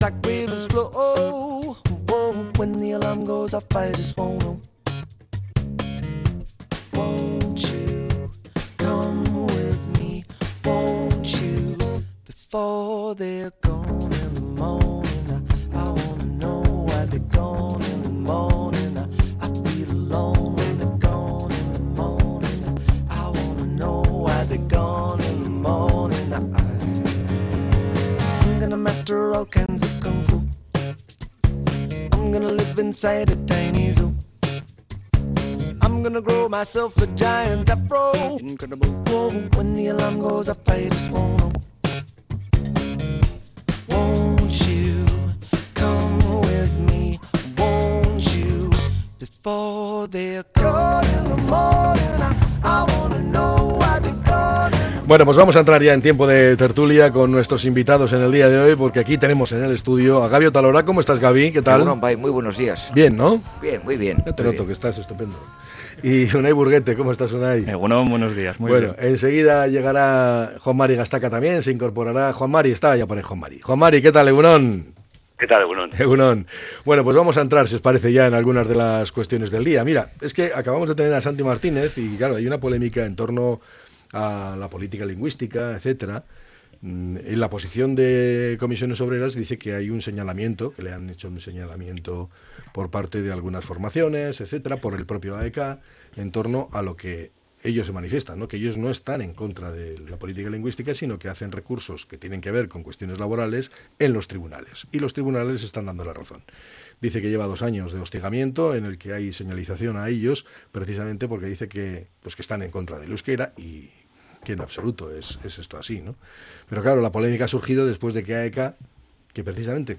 La Tertulia 23-03-26.